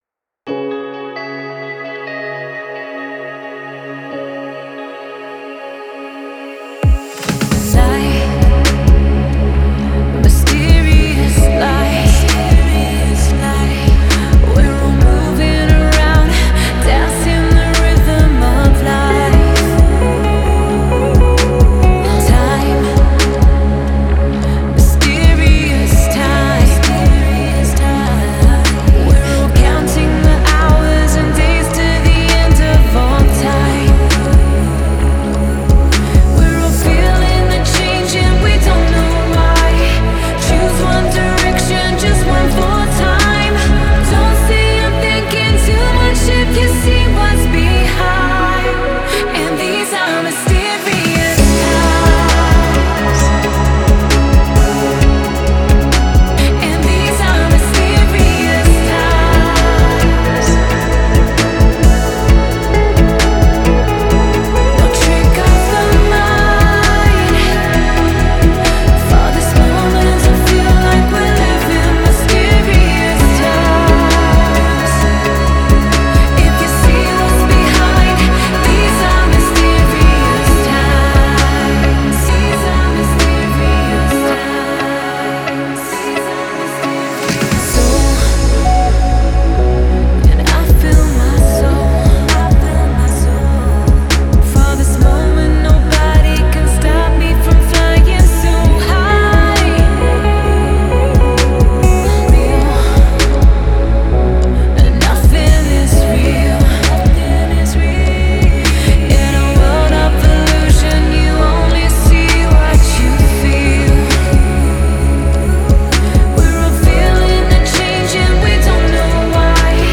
спокойная музыка